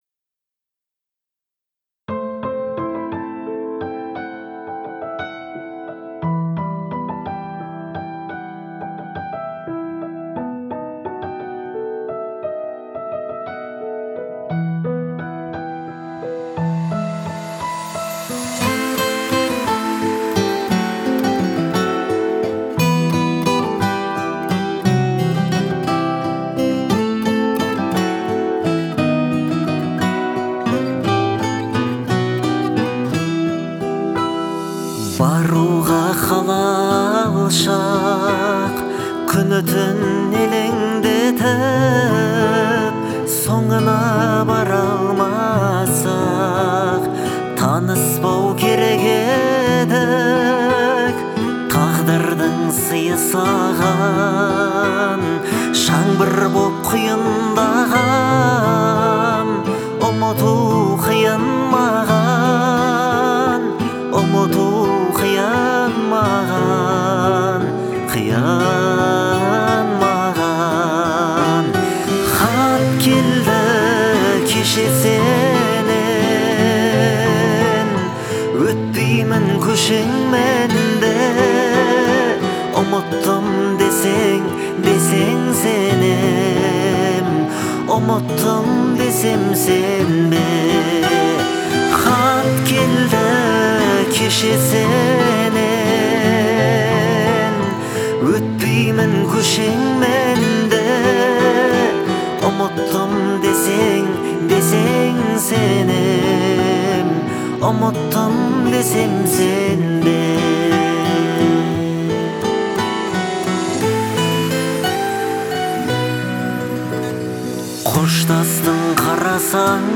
soulful вокалом, который передает всю палитру чувств